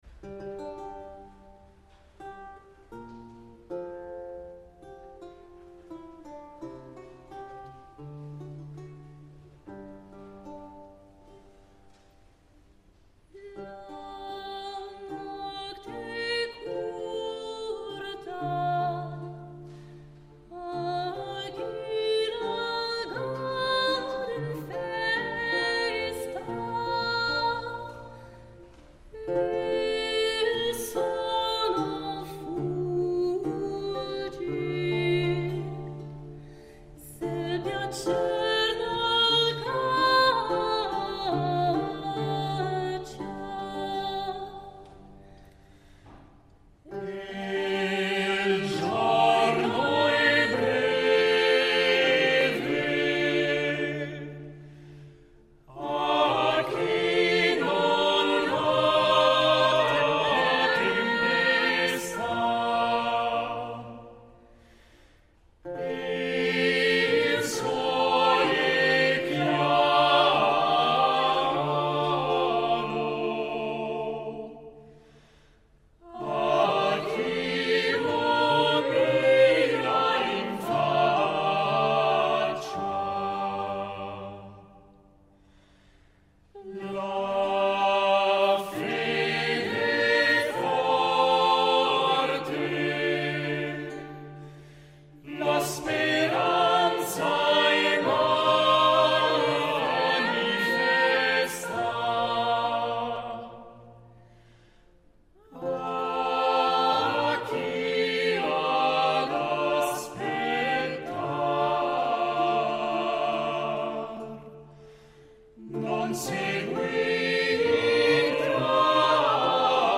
I ruoli sono stati affidati a giovani cantanti accompagnati da strumentisti provenienti dalla Svizzera, dalla Francia, dall’Italia e dalla Germania che si sono riuniti per approfondire il repertorio italiano – e fiorentino più precisamente – della fine del XV secolo.